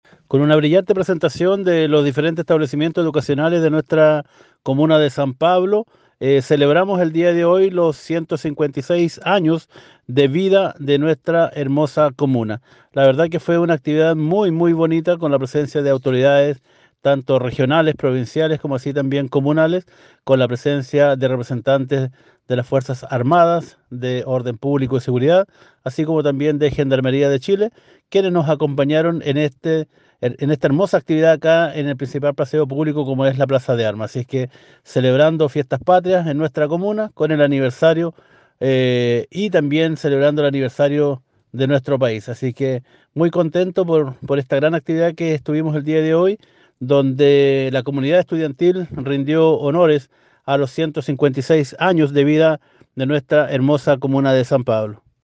Este viernes 08 de septiembre se realizó el desfile por el aniversario número 156 de la comuna de San Pablo, que contó con la presencia de las autoridades comunales, provinciales, parlamentarios y representantes de las distintas ramas de las fuerzas armadas.